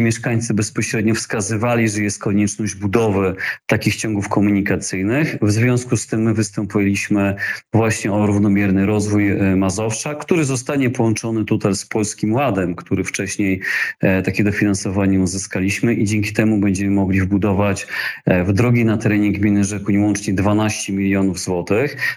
Wójt gminy Bartosz Podolak mówi, że to są drogi, na które długo oczekiwali mieszkańcy.